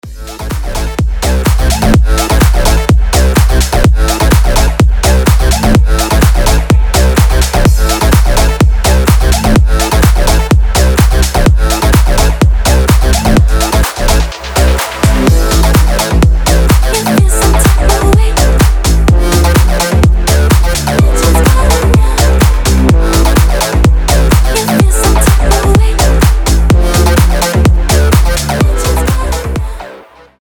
• Качество: 320, Stereo
громкие
EDM
энергичные
acid house